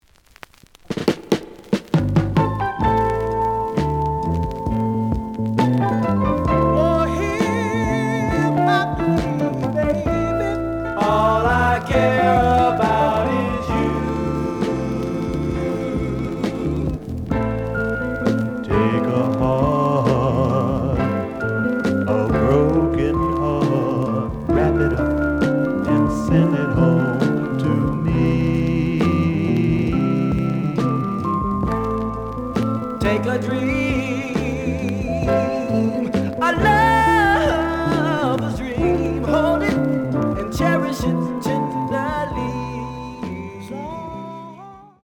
The audio sample is recorded from the actual item.
●Genre: Soul, 70's Soul
Slight affect sound.